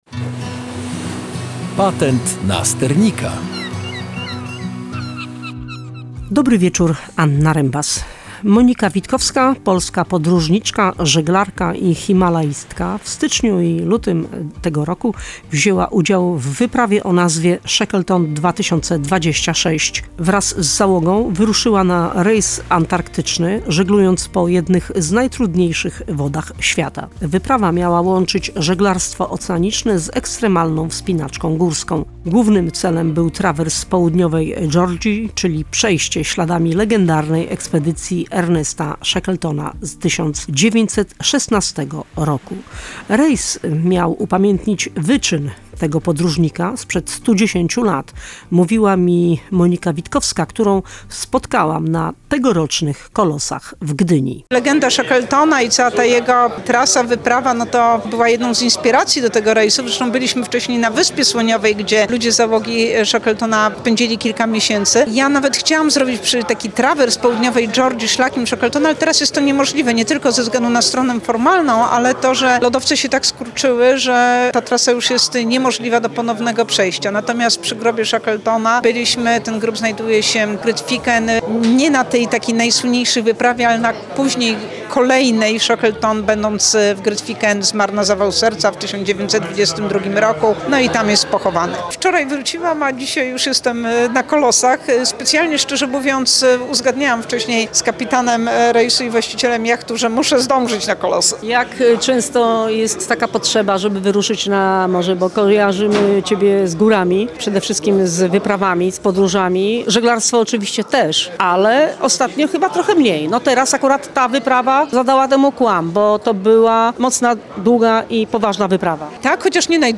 Nasza reporterka spotkała podróżniczkę na gdyńskich Kolosach.